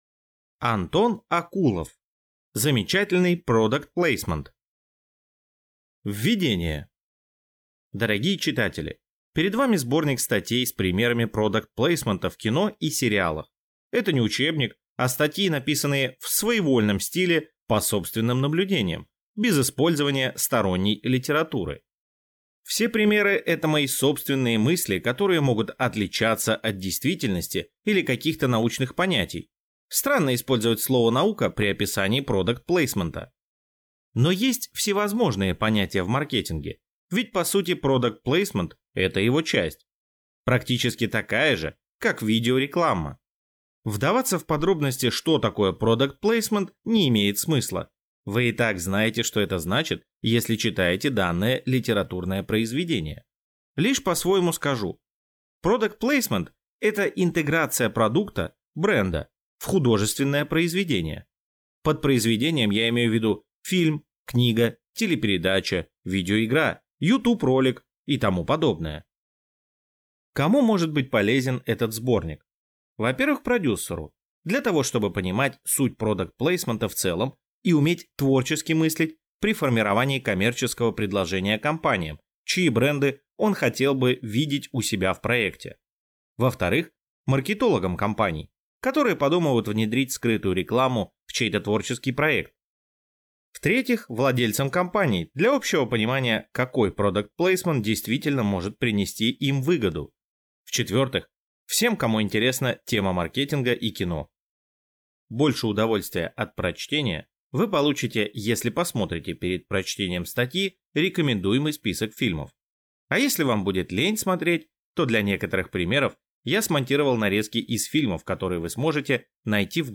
Аудиокнига Замечательный Product Placement | Библиотека аудиокниг